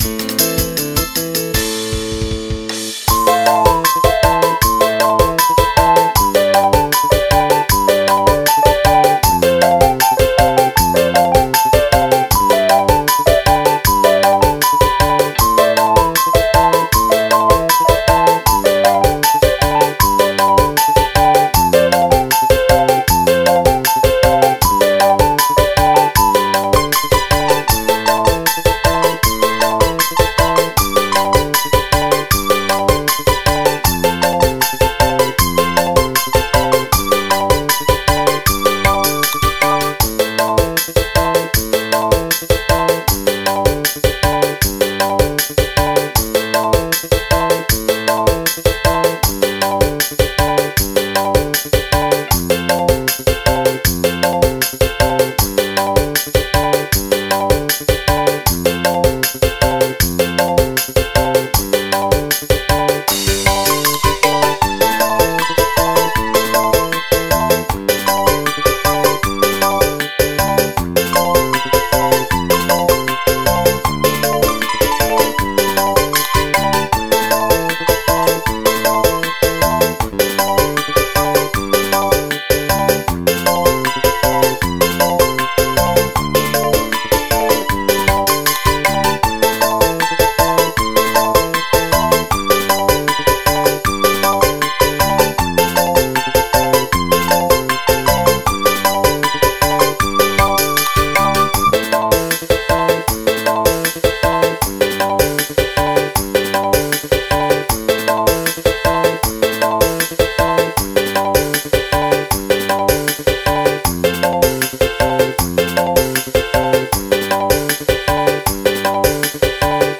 bernuansa dangdut